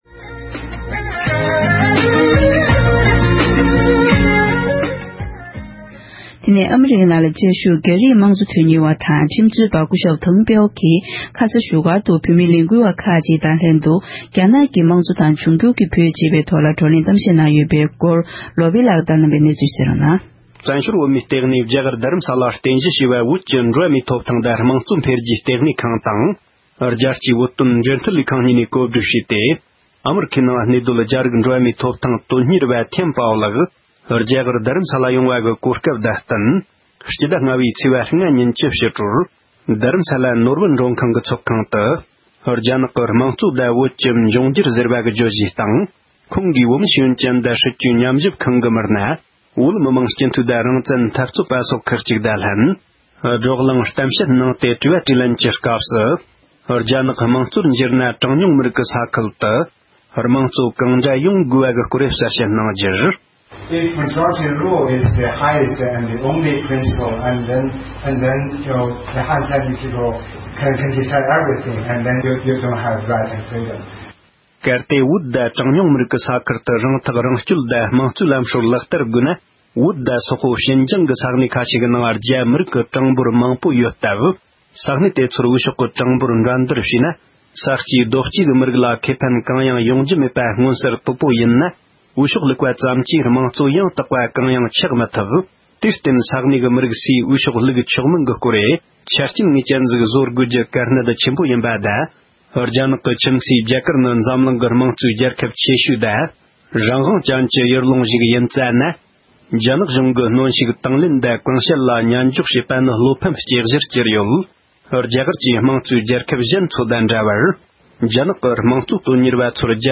རྒྱ་རིགས་དམངས་གཙོ་དོན་གཉེར་བས་རྡ་ས་ནས་བོད་དོན་གླེང་བ།